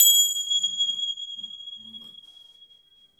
BELL LOUIS-S.WAV